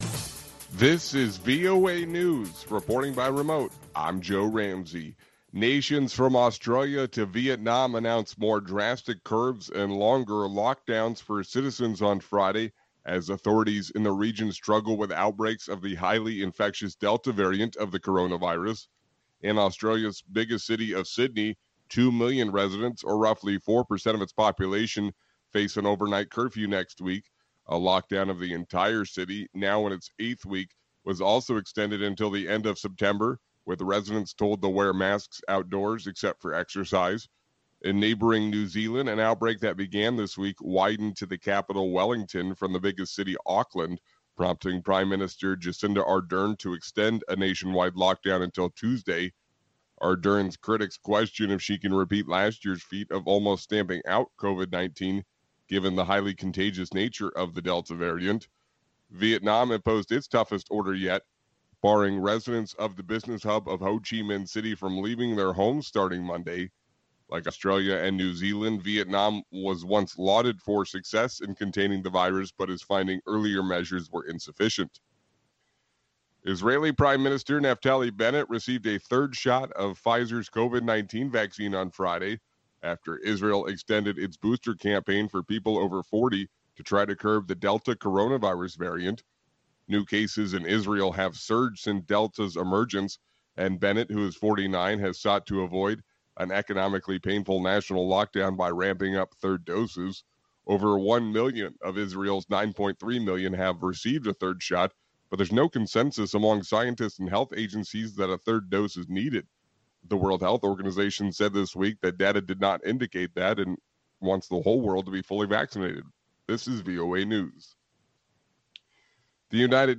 We bring you reports from our correspondents and interviews with newsmakers from across the world.